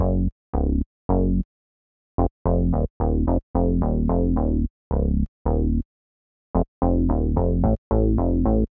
03 Bass PT4.wav